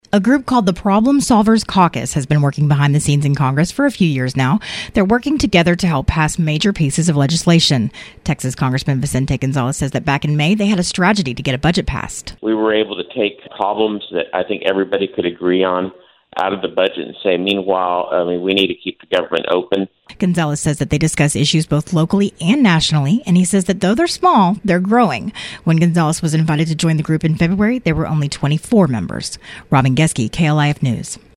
Texas Congressman Vicente Gonzalez (D-McAllen) says they don’t always see eye to eye, but that they respect each member and their point of view.